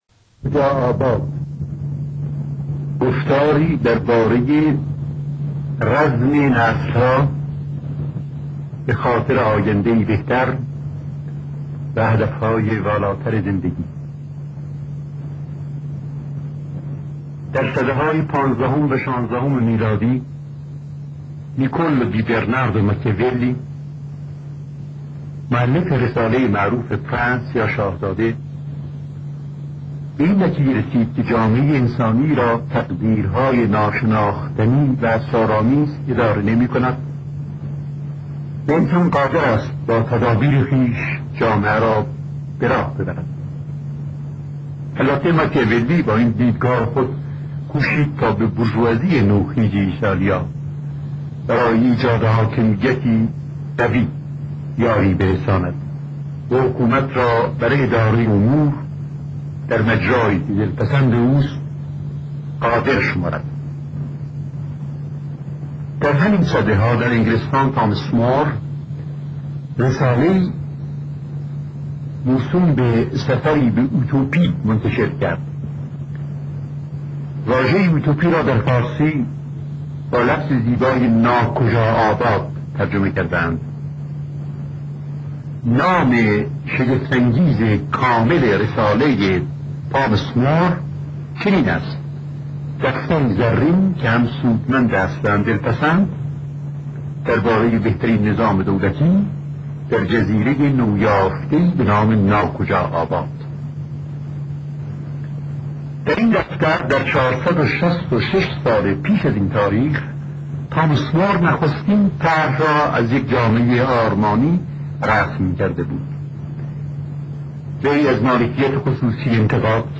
زن در شاهنامه ازکتاب راه رفتن روی ریل "با صدای فریدون تنکابنی "